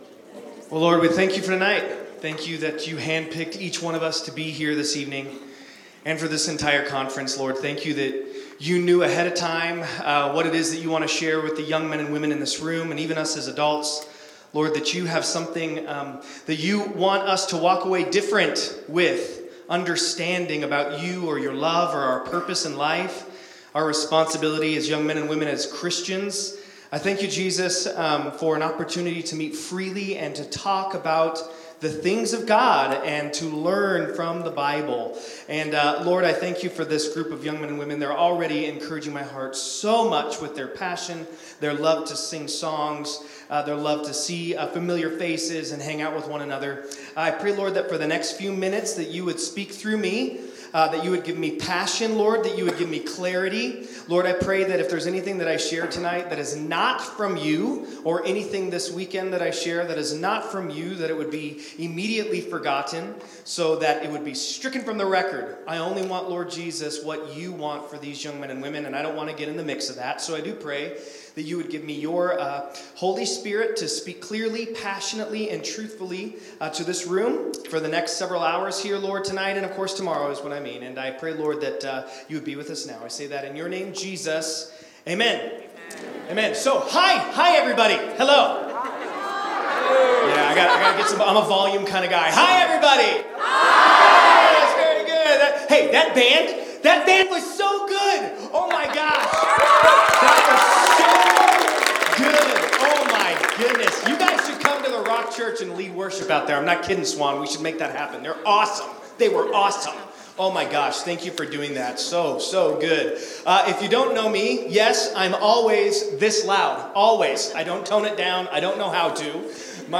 Winter Teen Conference - Teaching 1 - Summitview Church